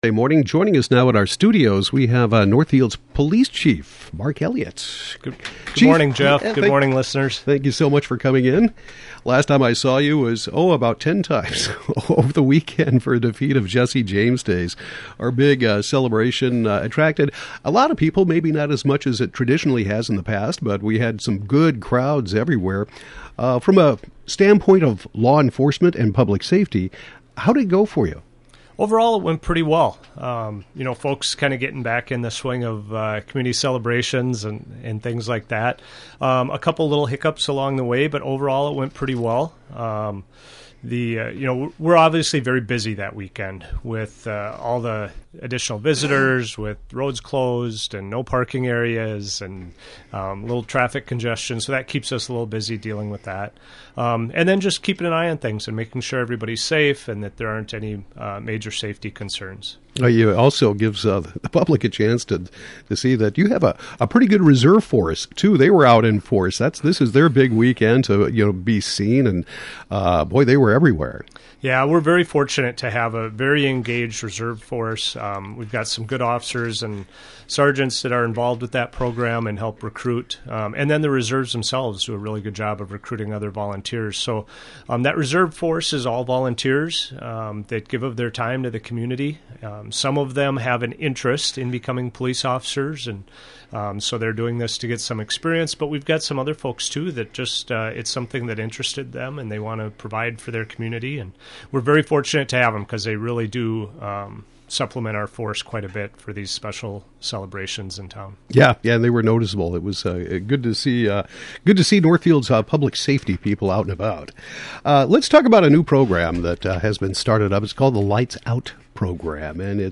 Northfield Police Chief Mark Elliott reviews the Defeat of Jesse James Days celebration, talks about the "Lights On" Program implemented by the Northfield Police Department recently, and more.